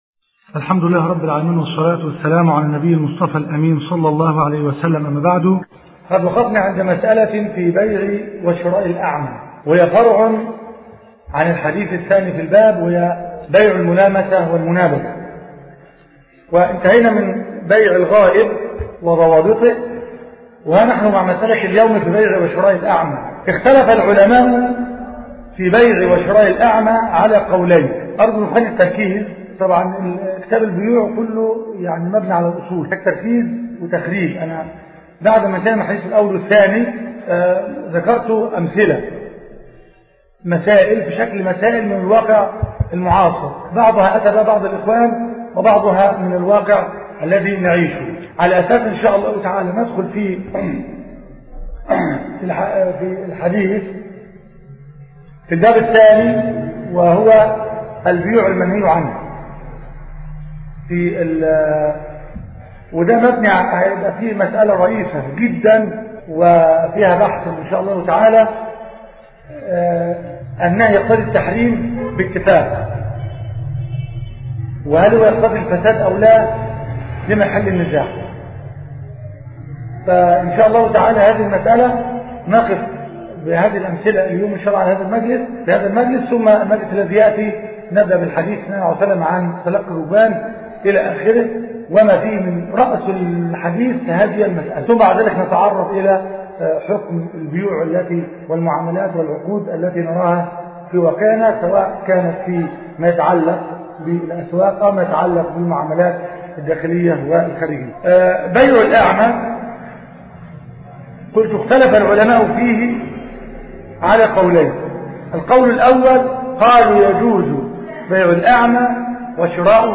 كتاب البيوع - المحاضرة الثامنة